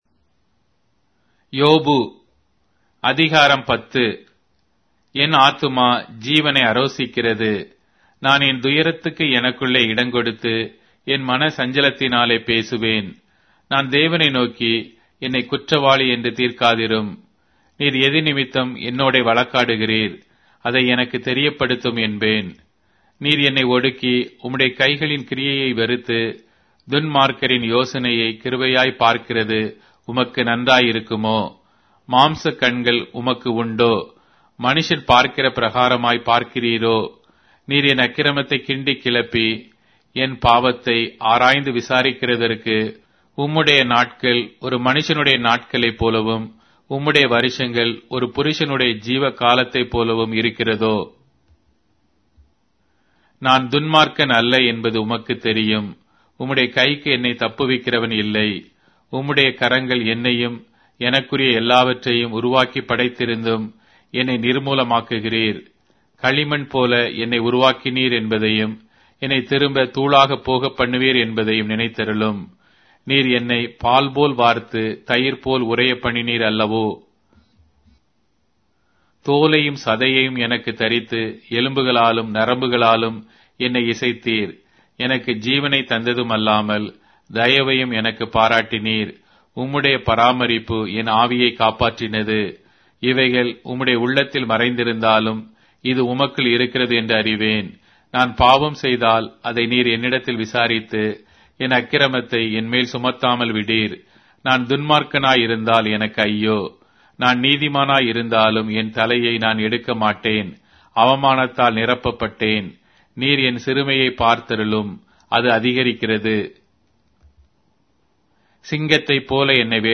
Tamil Audio Bible - Job 19 in Mkjv bible version